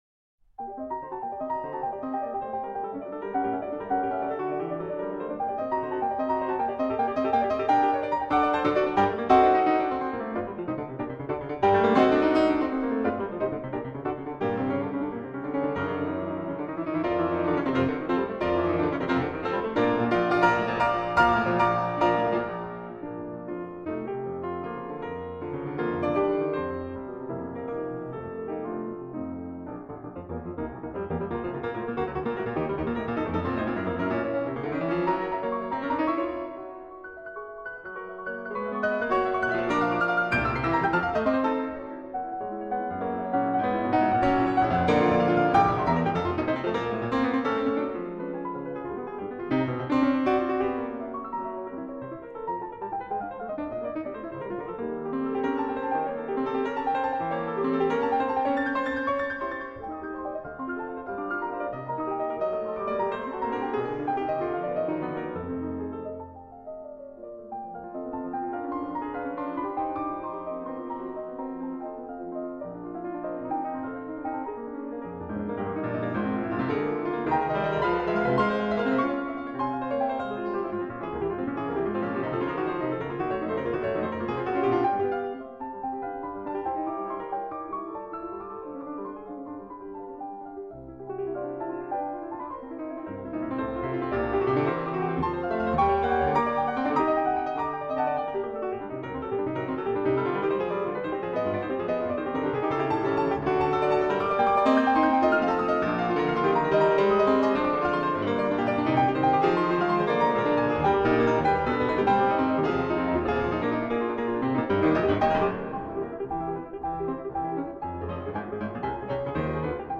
Piano  (View more Advanced Piano Music)
Classical (View more Classical Piano Music)